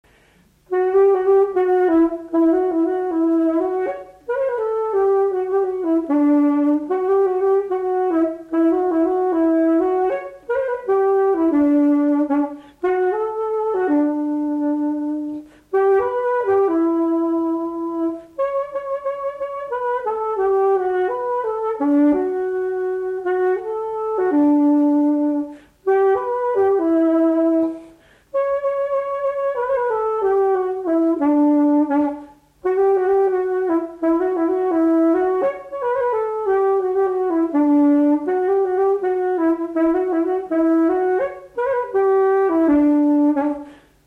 Marche de cortège de noces
instrumental
Pièce musicale inédite